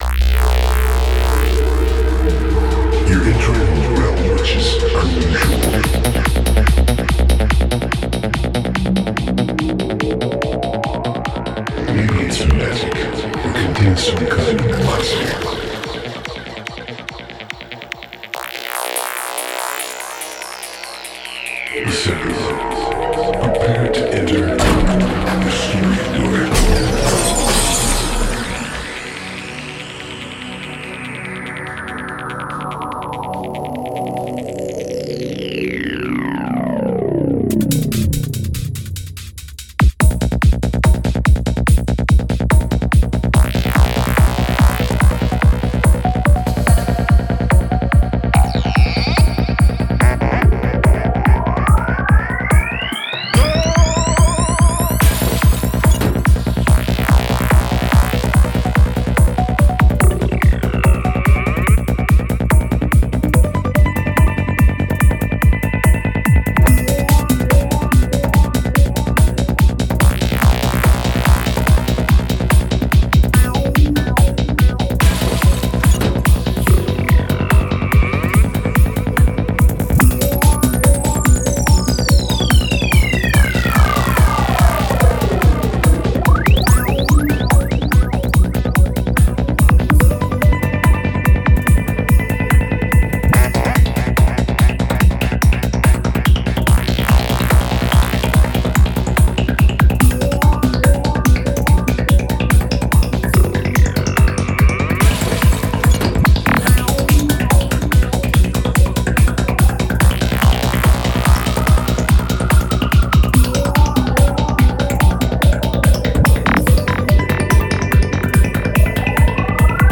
Psychadelic